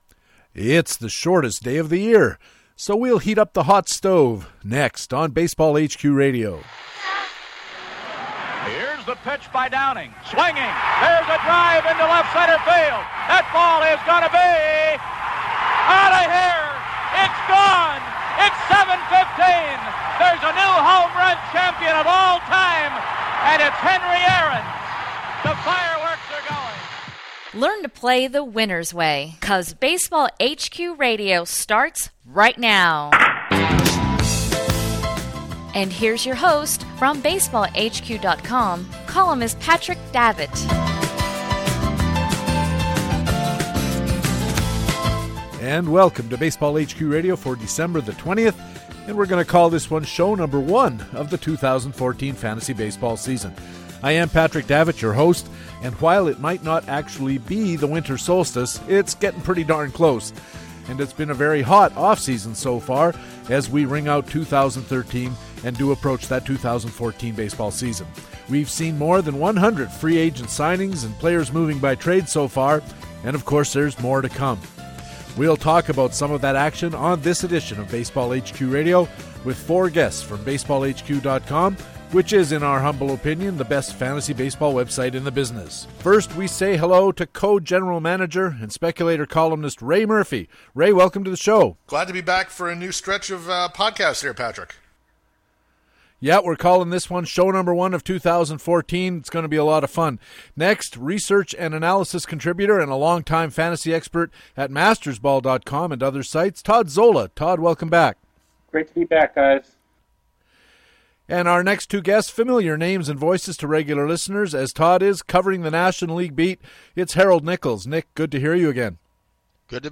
HQ RADIO Dec. 20, 2013: Hot Stove roundtable
Our HQ panel discusses the free-agent moves and trades so far this offseason...